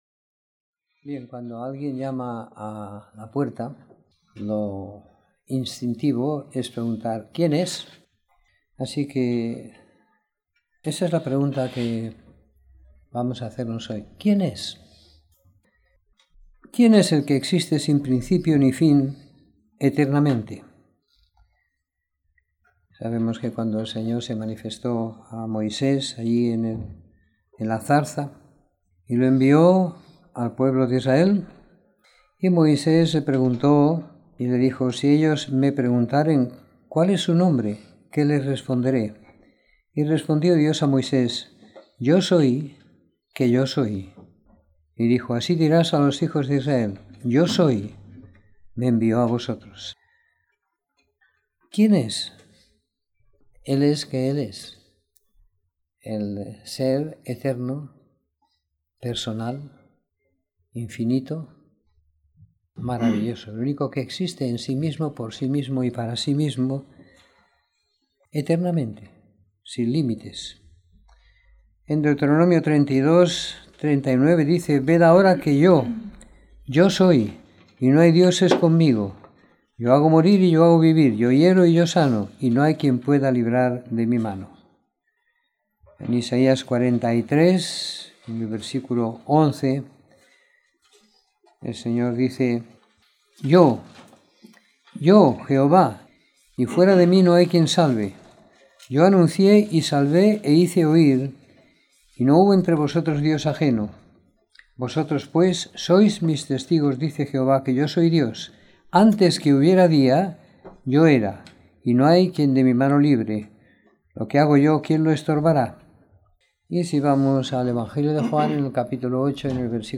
Reunión de Domingo por la Mañana